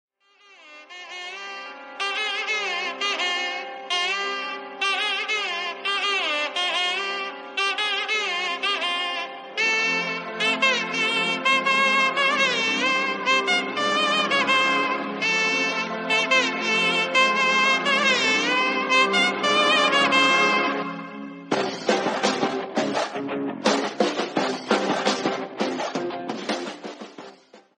devotional ringtone